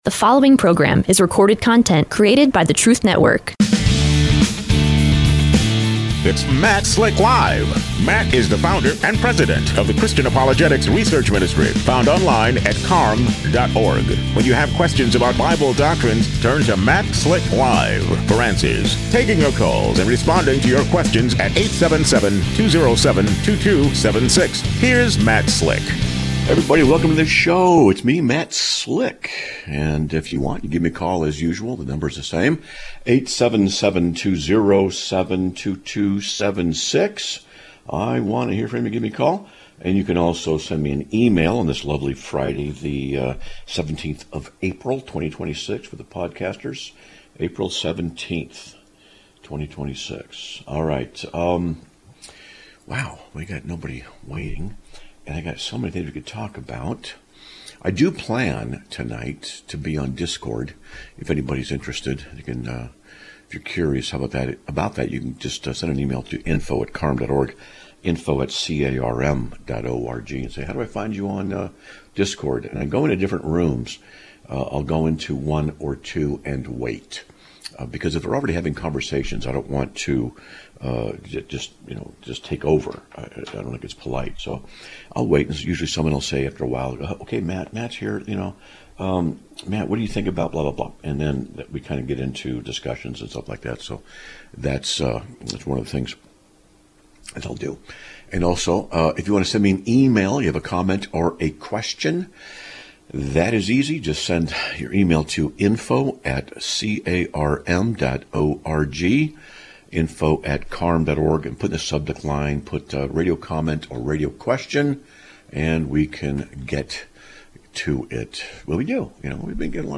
Live Broadcast of 04/17/2026